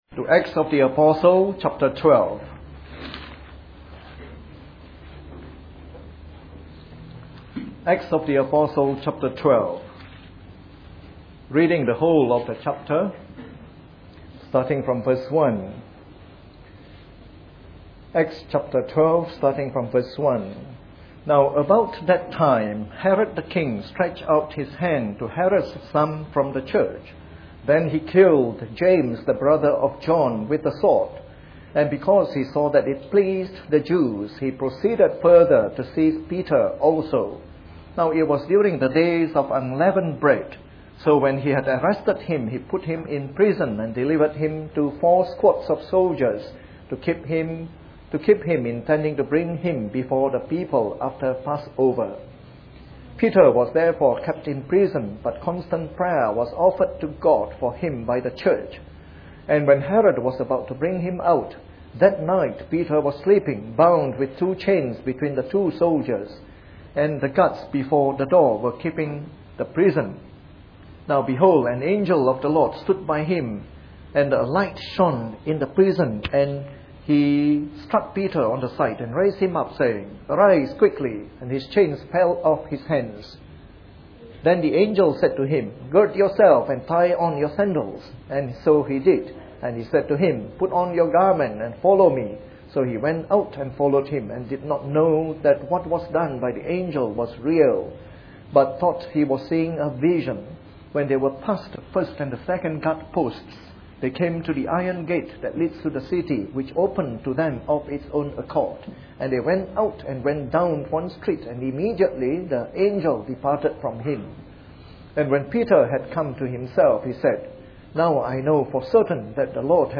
Part of our series on “The Acts of the Apostles” delivered in the Evening Service.